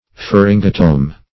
pharyngotome.mp3